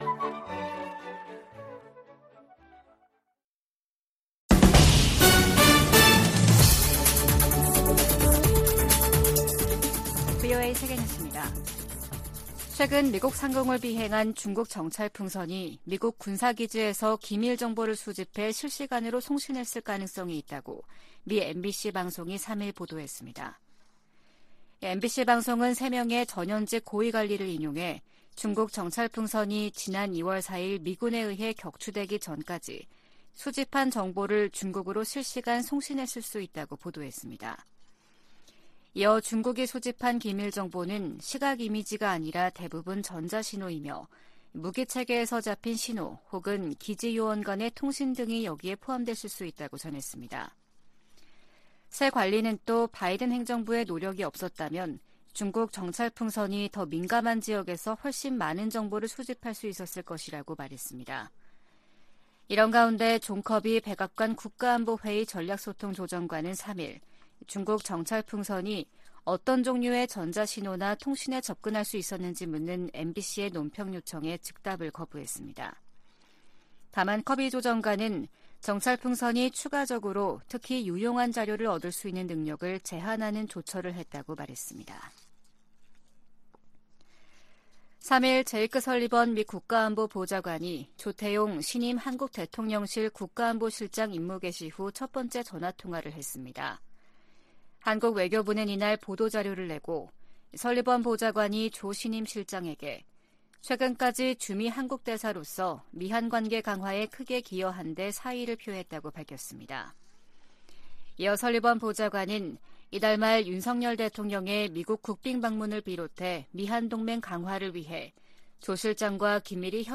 세계 뉴스와 함께 미국의 모든 것을 소개하는 '생방송 여기는 워싱턴입니다', 2023년 4월 4일 아침 방송입니다. 미국 상원은 최근 대통령 무력사용권 공식 폐지 법안을 가결했습니다.